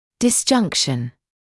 [dɪs’ʤʌŋkʃn][дис’джанкшн]разъединение; разобщение; отделение